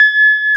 Index of /90_sSampleCDs/Roland L-CD701/KEY_Pop Pianos 4/KEY_Pop Pno + EP